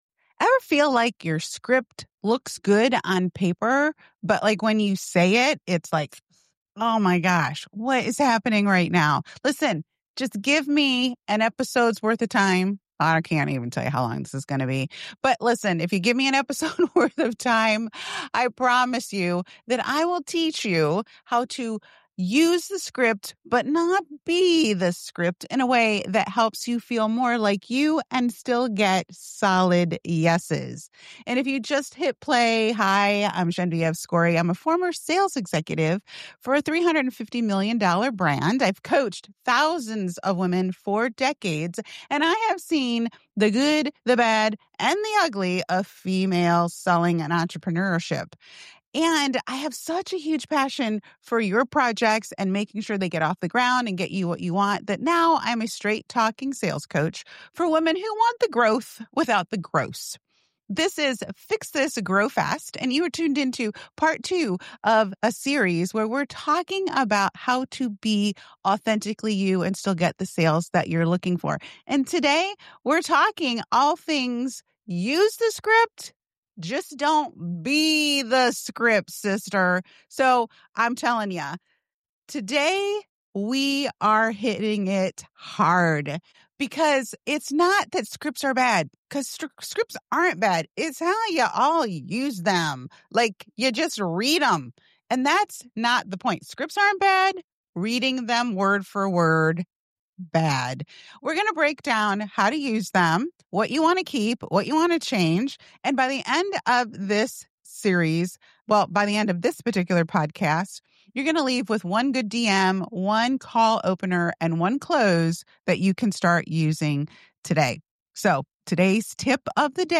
A heartfelt conversation on self-trust, mindset shifts, and healing without pressure.